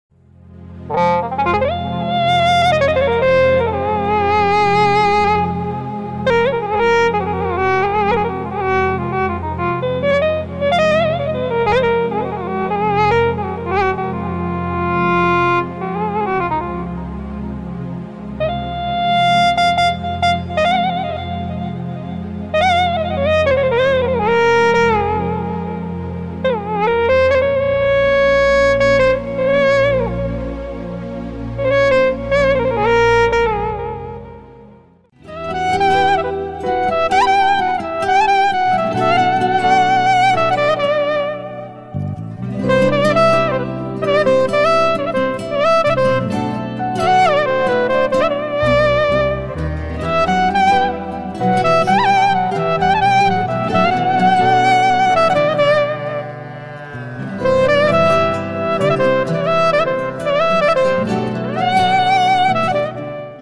أصوات الكلارنيت لل كورج كرونوس sound effects free download
Korg Kronos Set Klarinet Sounds